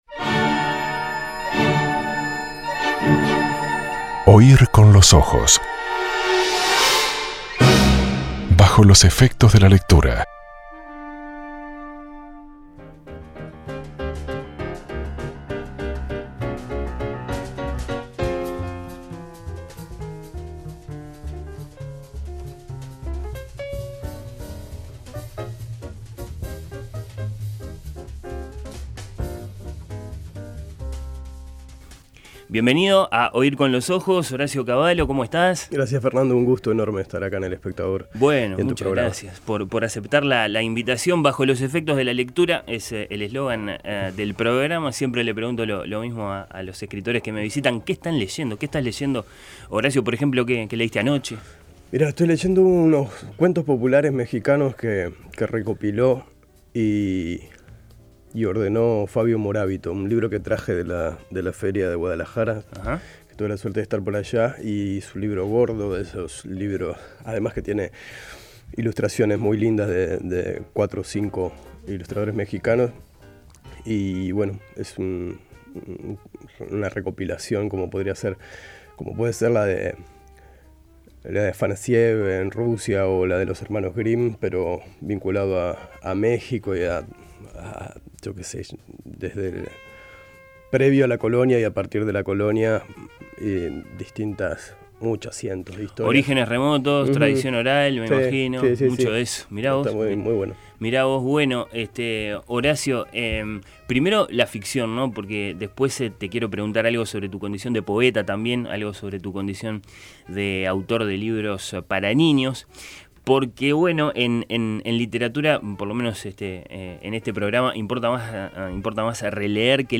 una conversación sobre libros y lecturas